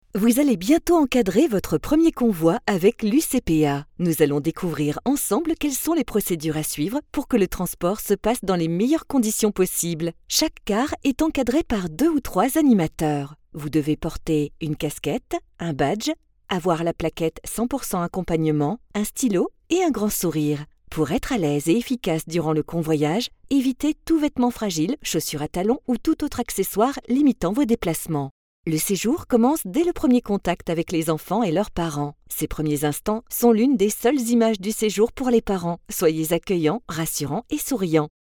Comédienne voix off féminine - adolescente, jeune, âge moyen, mûre...
Sprechprobe: eLearning (Muttersprache):
Démo voix acap ELEARNING.mp3